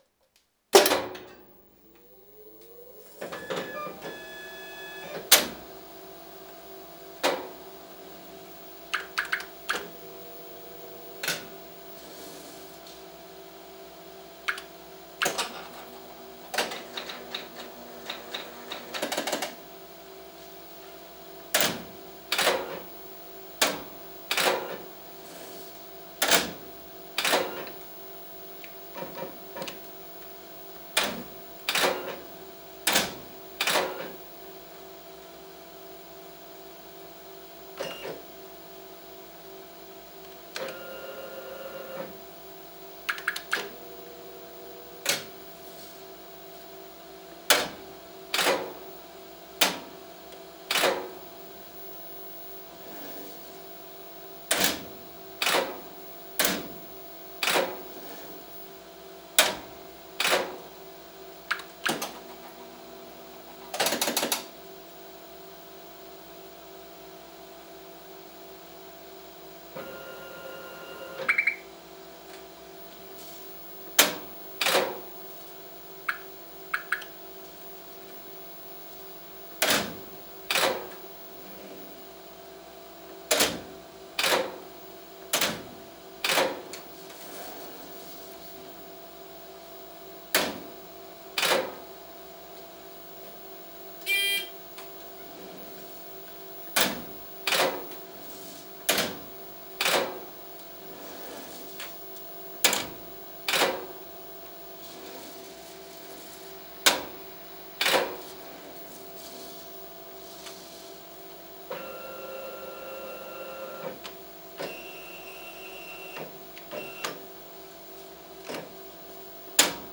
●PAVO-JVの動作音
2011年３月に入手した手動写植機「PAVO-JV」の動作音を録音してみました。
生に限りなく近い音を記録するためダイナミックレンジ（音量の大小）を加工していませんので、突然大きな動作音が聞こえます。
動作音全般
録音機：SONY 録再Hi-MDウォークマン MZ-RH1　リニアPCMステレオ録音
電子制御された手動写植機の音です。電動のため動作音は力強く、パルスモーターやブザーによる電気的な音色が特徴です。
動作音全般　電源投入〜機械原点移動動作で始まり、主レンズ／変形レンズ選択、採字〜枠固定〜印字操作、送り、キー入力音「ピョッピョッ」、エラー音「ピコピコ」、ブザー「ビー」といった様々な動作音を収録しています。